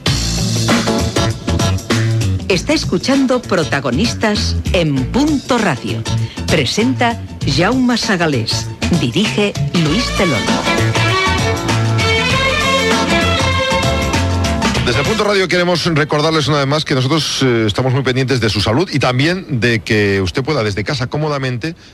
Indicatiu del programa i inici d'un tema sobre salut.
Info-entreteniment
FM